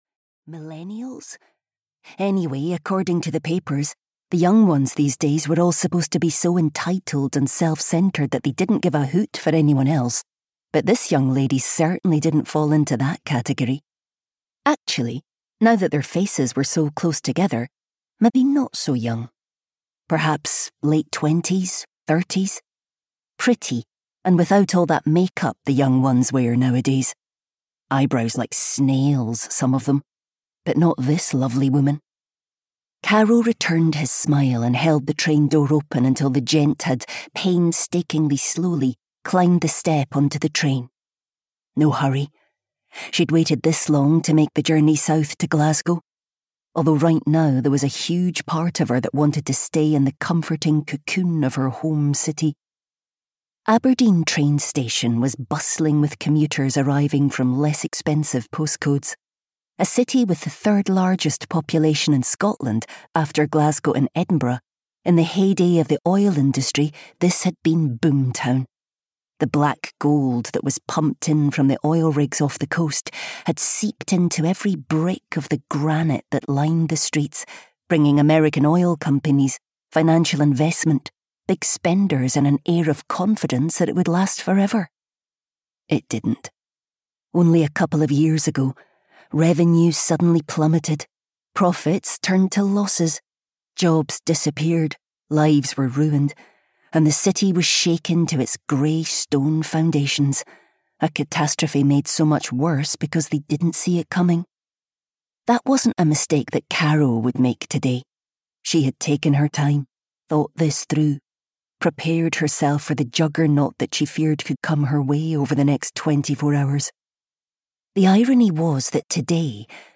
Audio knihaOne Day in Winter (EN)
Ukázka z knihy